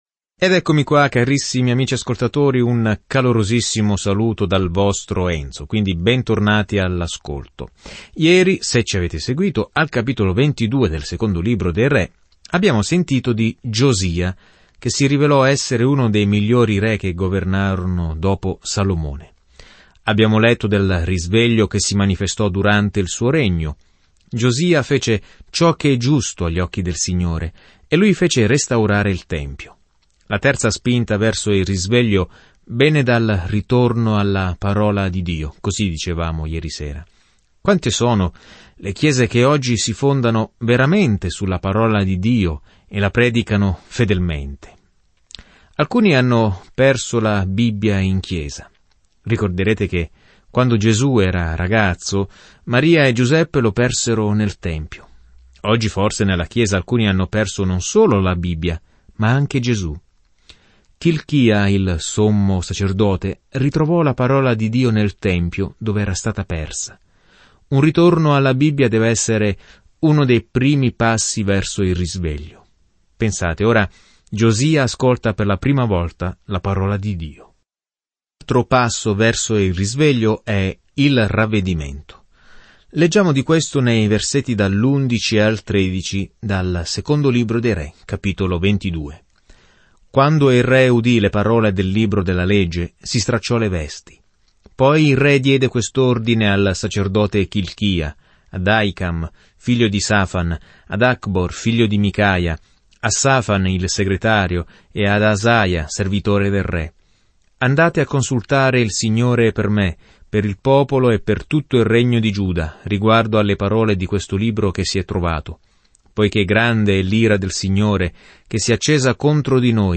Scrittura Secondo libro dei Re 22:11-20 Secondo libro dei Re 23 Giorno 13 Inizia questo Piano Giorno 15 Riguardo questo Piano Il libro di Secondo Re racconta come le persone persero di vista Dio e come Egli non le dimenticò mai. Viaggia ogni giorno attraverso 2 Re mentre ascolti lo studio audio e leggi versetti selezionati della parola di Dio.